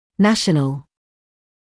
[‘næʃnəl][‘нэшнэл]национальный, государственный